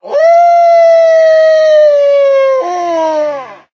howl2.ogg